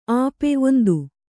♪ āpe